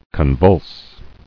[con·vulse]